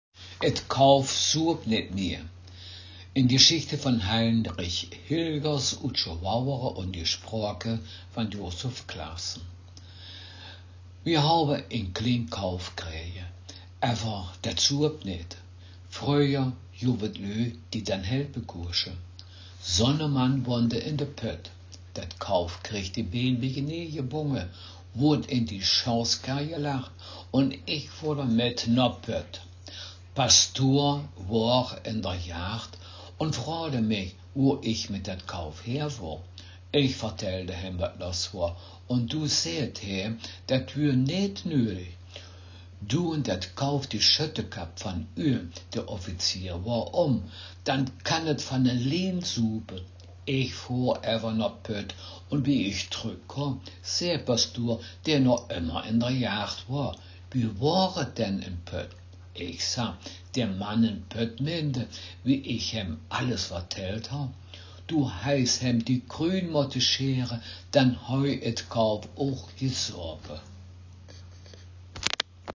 Text Mundart
Gangelter-Waldfeuchter-Platt
Geschichte